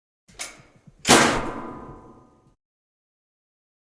Washing Machine Door CLose
Foley
Washing Machine Door CLose is a free foley sound effect available for download in MP3 format.
yt_dV7x2KB7oHY_washing_machine_door_close.mp3